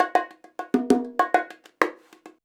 100BONG11.wav